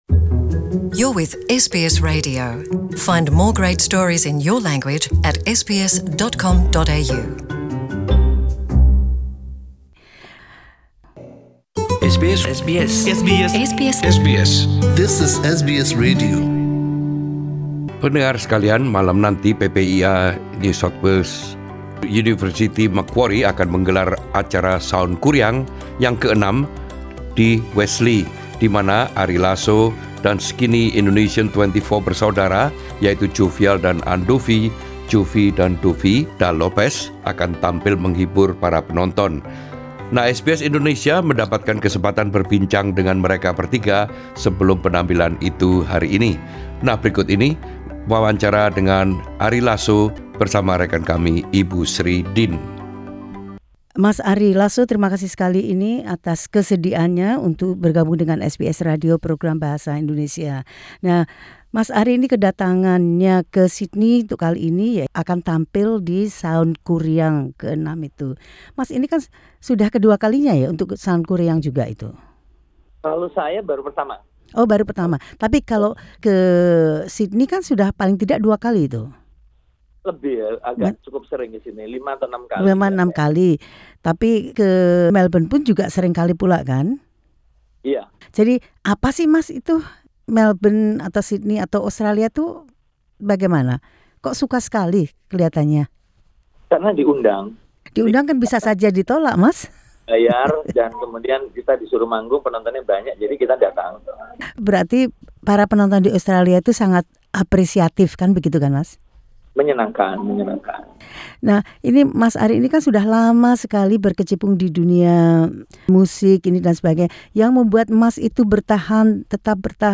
Penyanyi, musisi, juri tv talent show yang juga pelaku bisnis Ari Lasso yang akan tampil di acara Soundquriang tahun ini di Sydney yang diselenggarakan oleh PPIA Universitas Macquarie, berbicara tentang apa yang membuat dapat bertahan sebagai performer dan juga sebagai wirausaha.